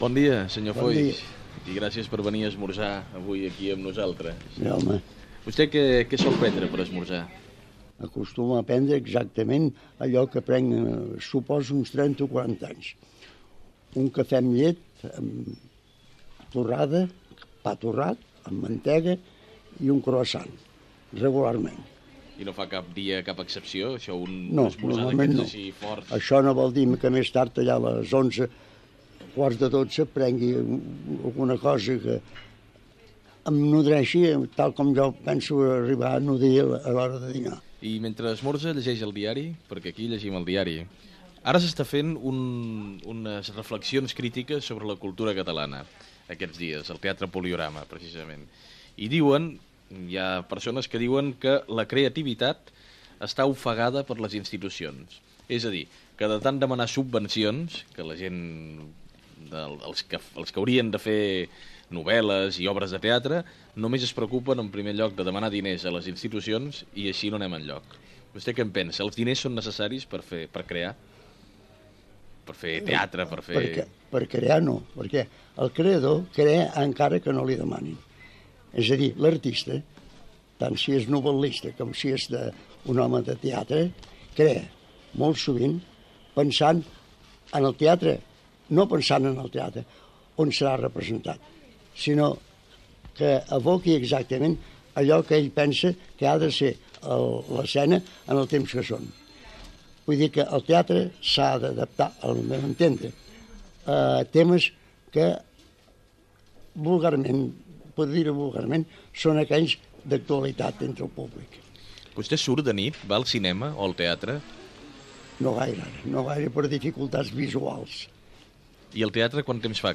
Entrevista a l'escriptor Josep Vicenç Foix, a la seva pastisseria de Sarrià, sobre allò que esmorza i temes culturals i socials del moment
Info-entreteniment